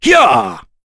Dakaris-Vox_Attack2.wav